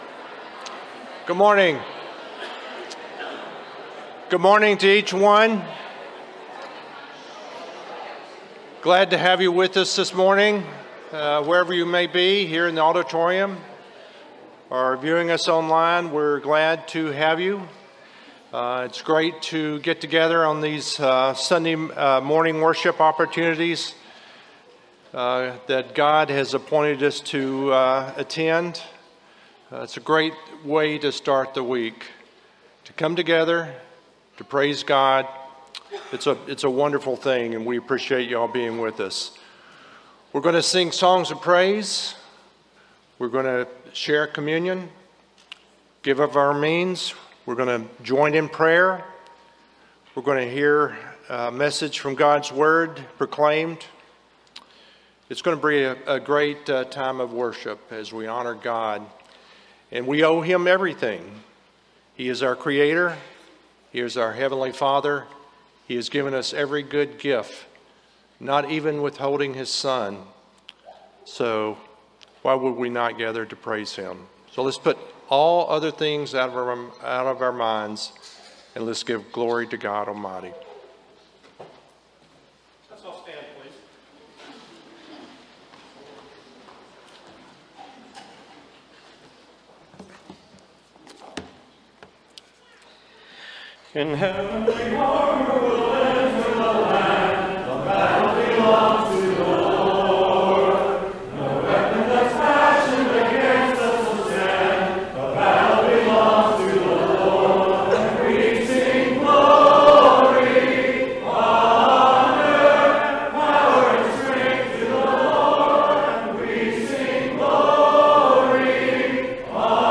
Genesis 3:12, English Standard Version Series: Sunday AM Service